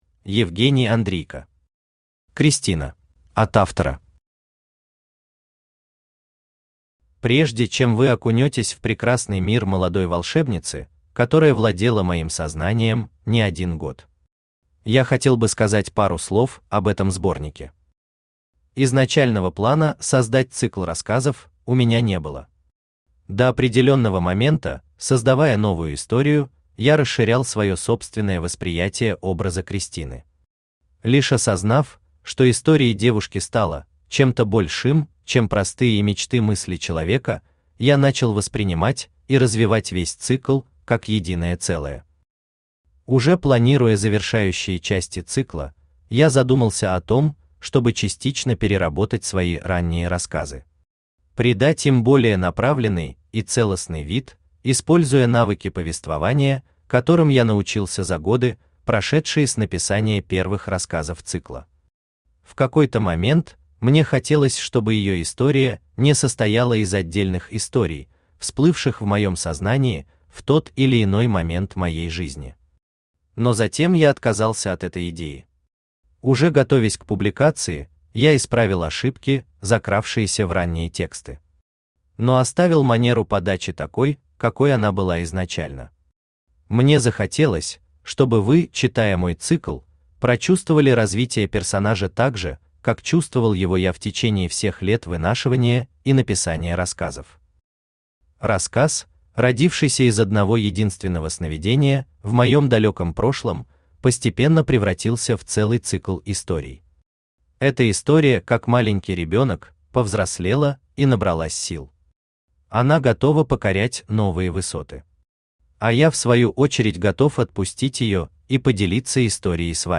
Читает: Авточтец ЛитРес
Аудиокнига «Кристина».